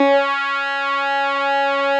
snes_synth_049.wav